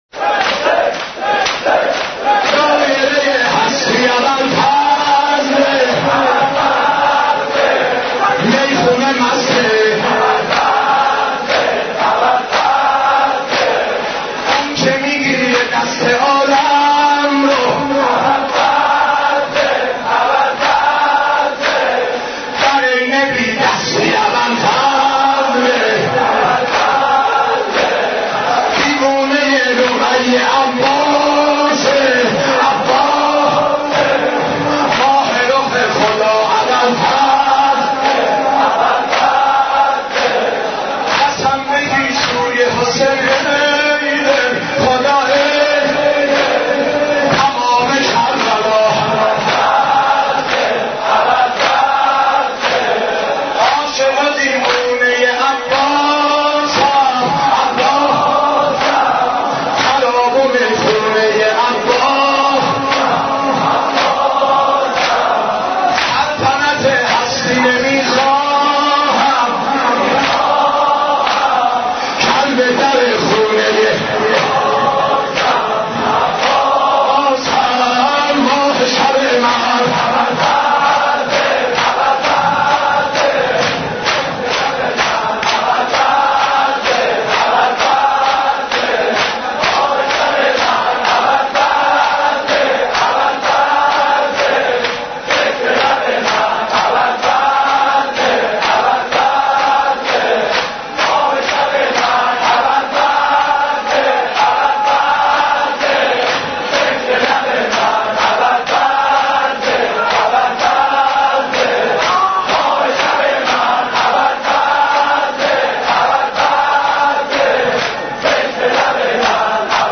حضرت عباس ع ـ شور 13